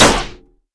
rifle_hit_metal1.wav